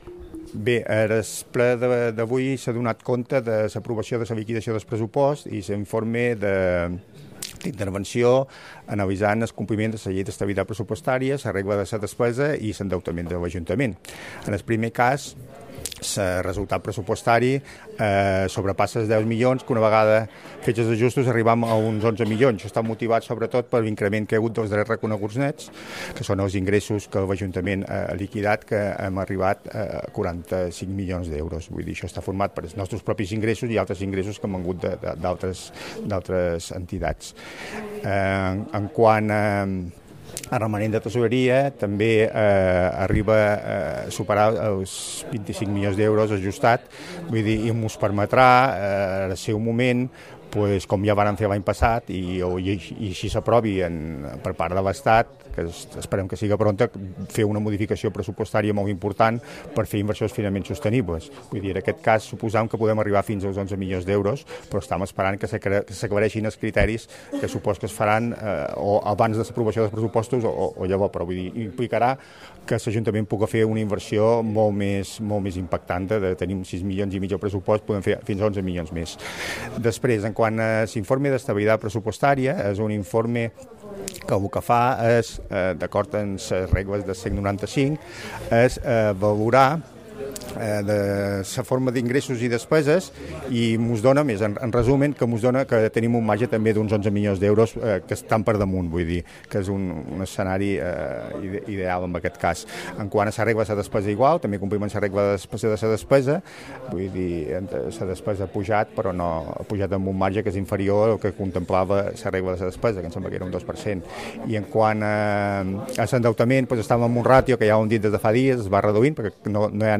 Corte de voz del concejal Pedro Juan-Liquidación presupuesto